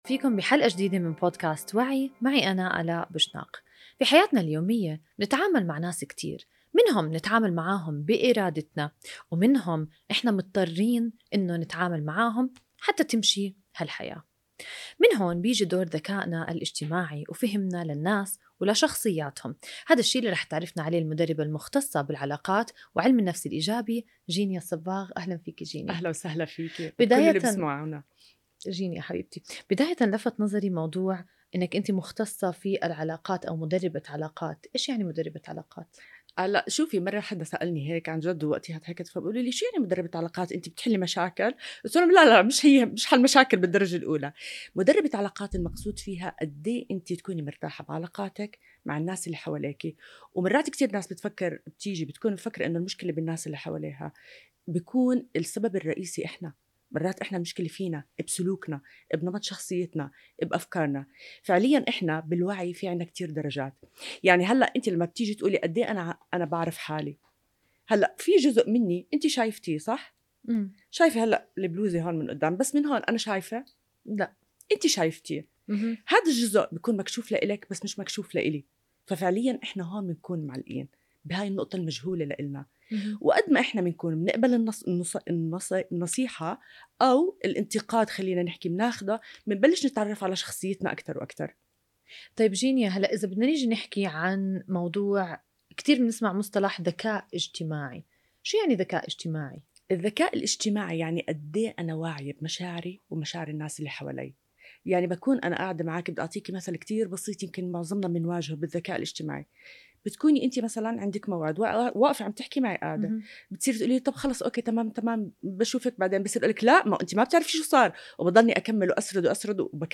بودكاست “ما وراء الشخصيات” من “وعي” يغوص في تفاصيل الذكاء الاجتماعي وأنماط الشخصيات، من النرجسية إلى الحساسة، مرورًا بالاكتفاء الذاتي والتوازن بين التخطيط والمرونة. عبر حوار شيق مع المدربة